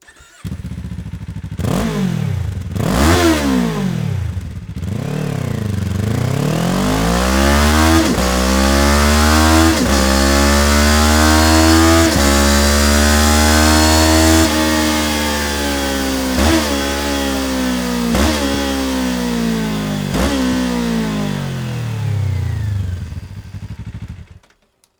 純正と比較して35％(1.5kg)の軽量化を実現しており、重厚なサウンド、スロットルレスポンス、そして外観のすべてにおいて、開発段階からエンジニアが重点を置いて設計されています。